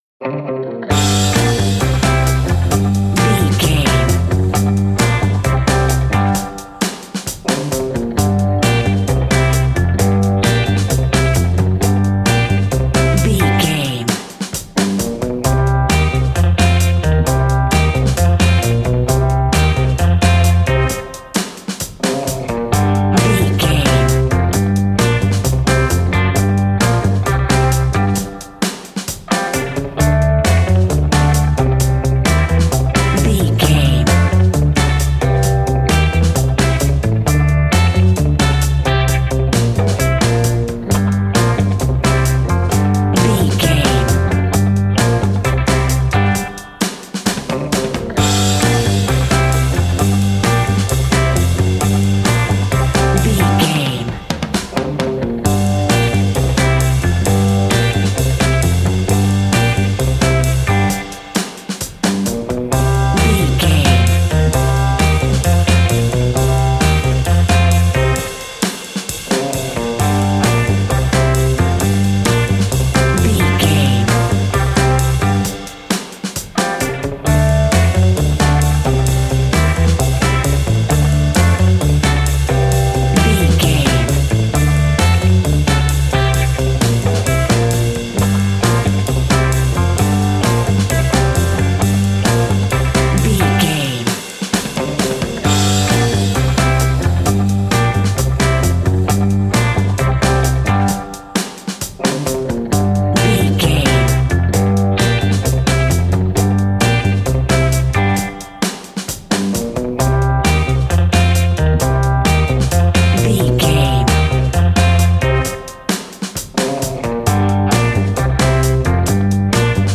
Aeolian/Minor
cool
uplifting
bass guitar
electric guitar
drums
cheerful/happy